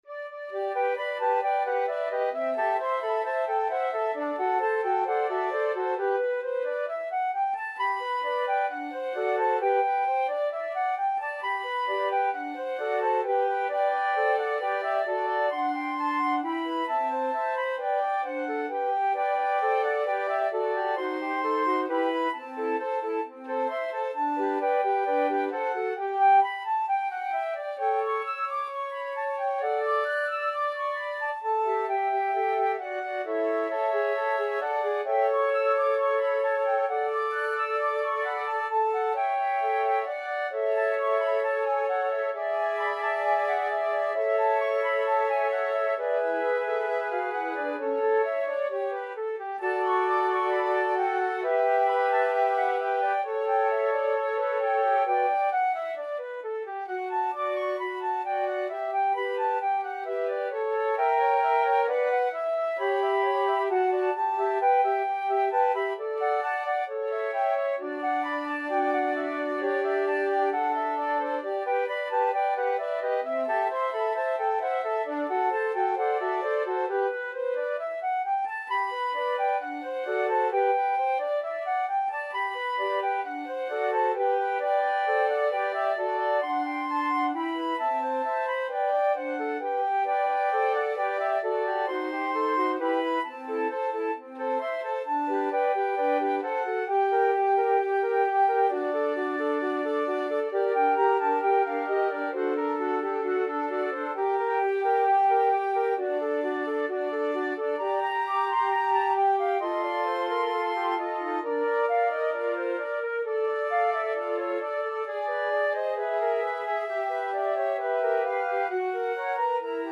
2/2 (View more 2/2 Music)
=132 Allegro assai (View more music marked Allegro)
Flute Quartet  (View more Intermediate Flute Quartet Music)
Classical (View more Classical Flute Quartet Music)